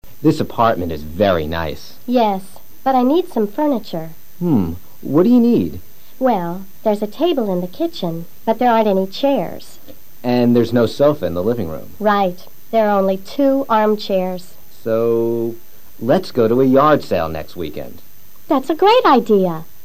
Chris le ofrece ayuda a Linda para conseguir algunos muebles. Escucha atentamente el diálogo y repítelo luego simultáneamente.